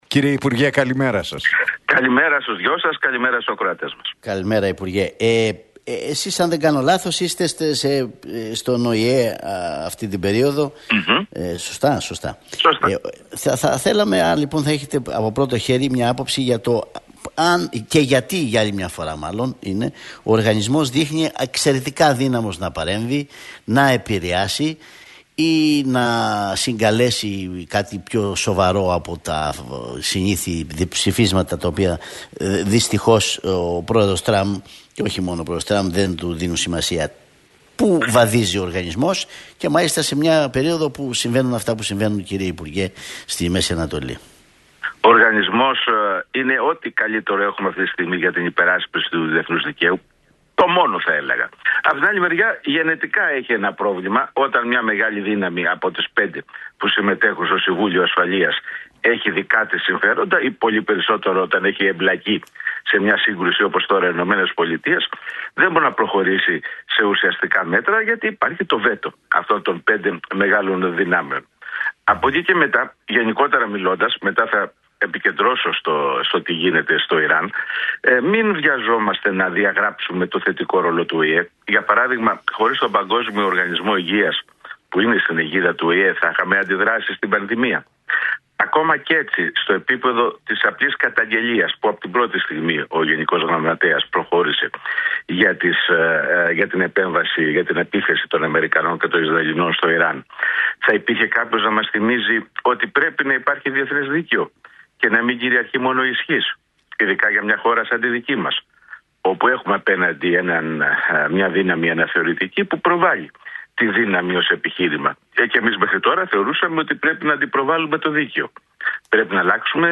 μίλησε ο πρώην υπουργός Εξωτερικών, Γιώργος Κατρούγκαλος στον Realfm 97,8